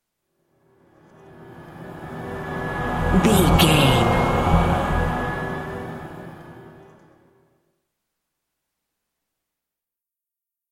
Thriller
Aeolian/Minor
E♭
Slow
synthesiser
tension
ominous
dark
suspense
haunting
creepy